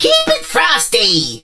lou_start_vo_01.ogg